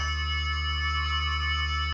audio: Converted sound effects
CBHQ_CFO_magnet_loop.ogg